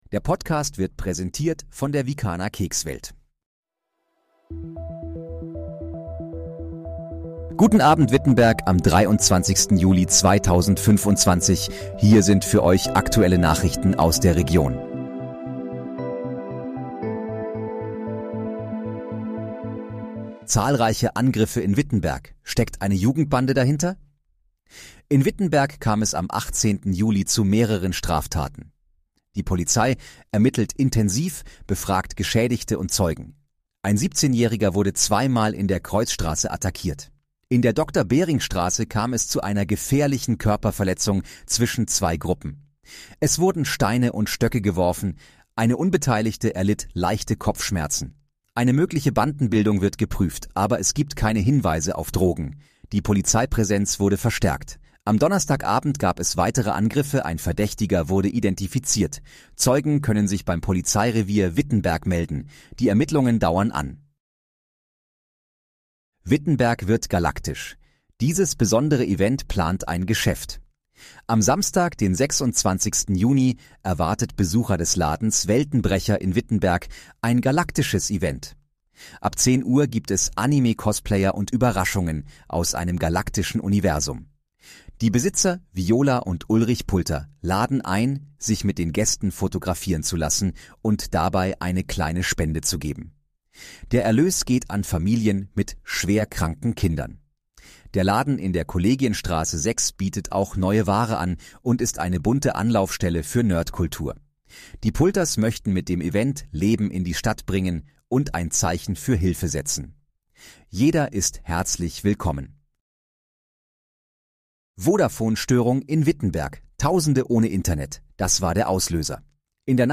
Guten Abend, Wittenberg: Aktuelle Nachrichten vom 23.07.2025, erstellt mit KI-Unterstützung
Nachrichten